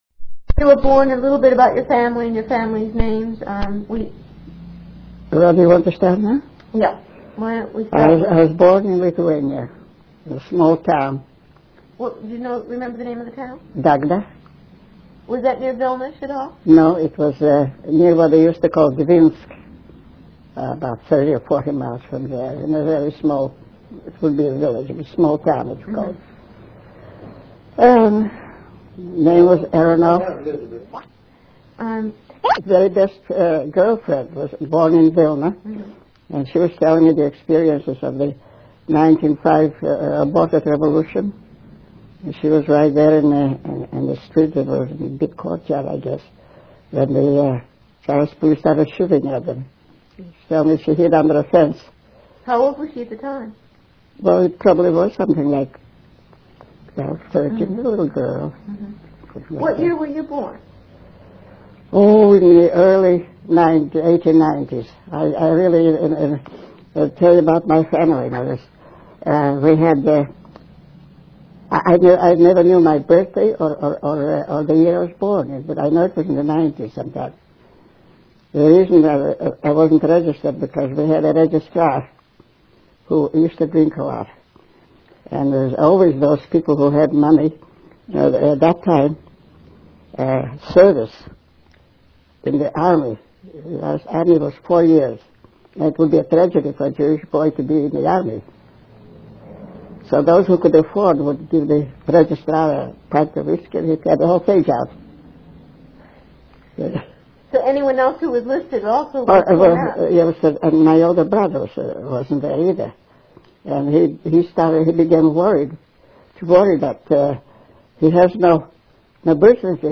INTERVIEW DESCRIPTION